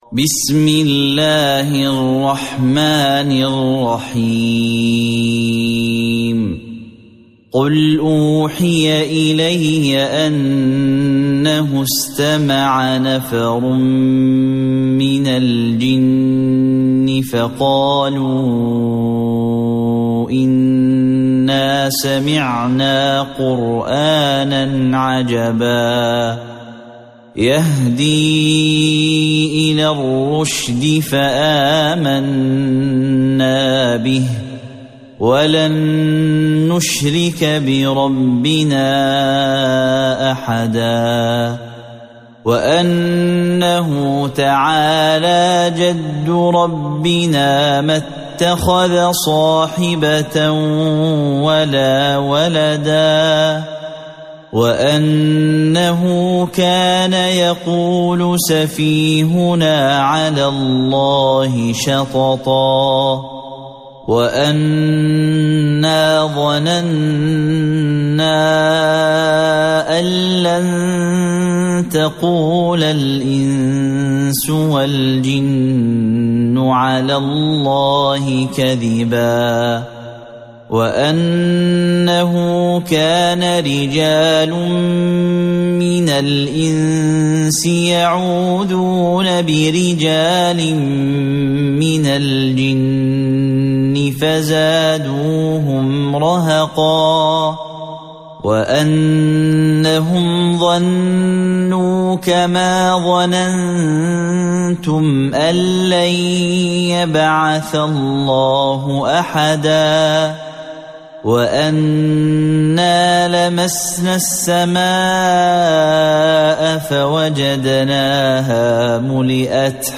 سورة الجن | القارئ